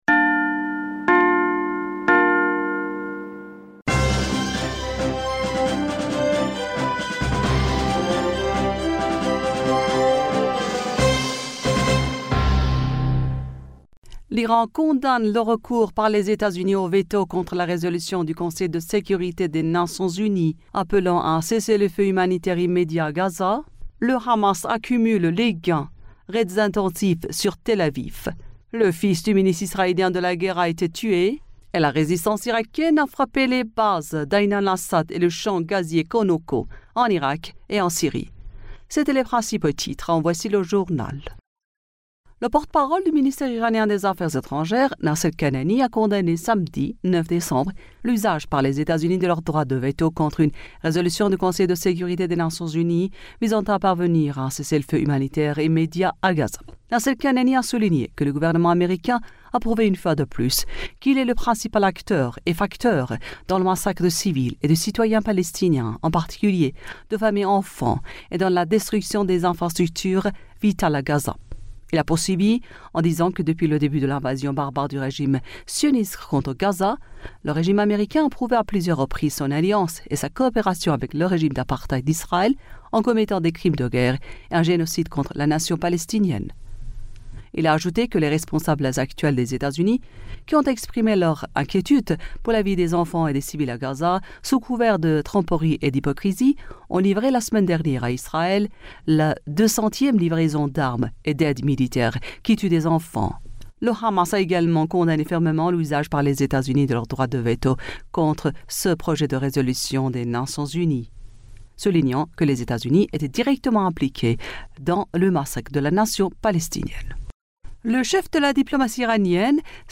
Bulletin d'information du 09 Decembre 2023